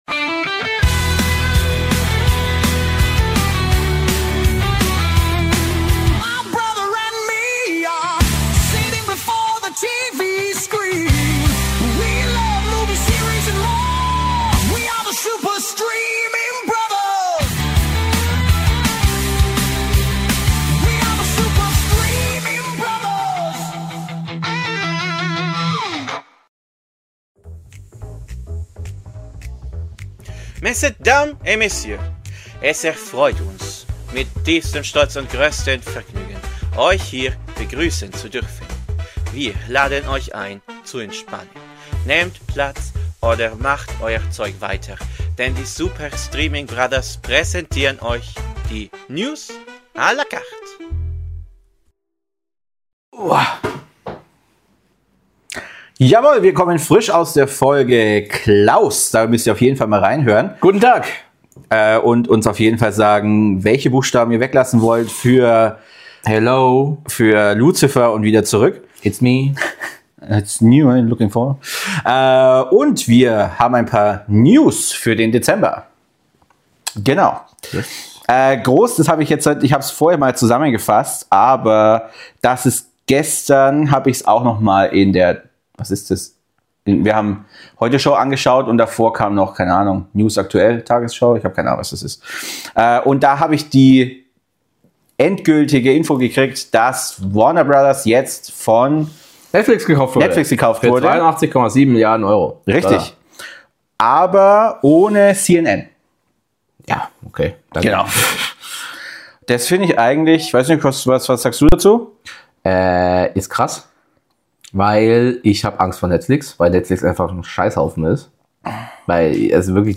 Ungekürzt, ohne Cuts.